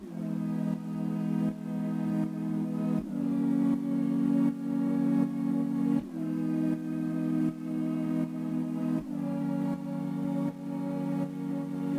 罗非垫
描述：lofi pad 80bpm
Tag: 80 bpm LoFi Loops Pad Loops 2.02 MB wav Key : Unknown